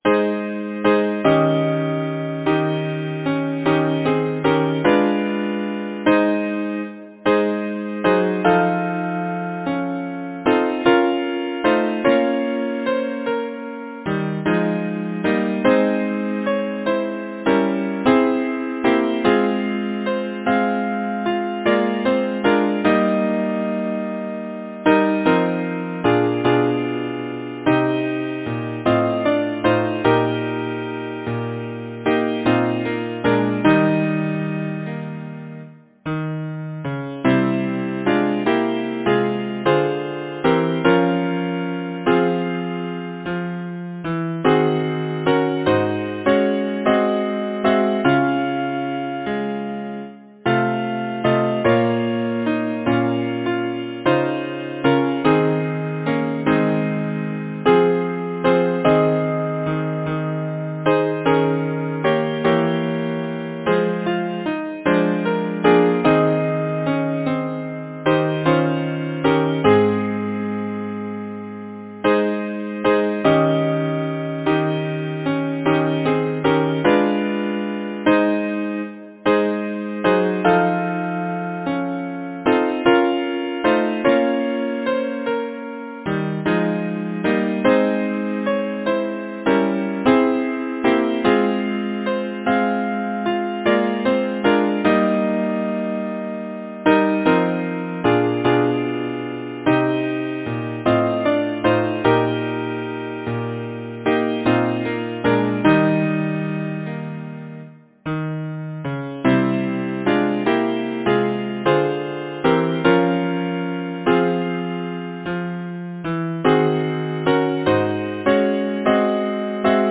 Title: Isle of Beauty Composer: Samuel Coleridge-Taylor Lyricist: Thomas Haynes Bayly Number of voices: 4vv Voicing: SATB Genre: Secular, Partsong
Language: English Instruments: A cappella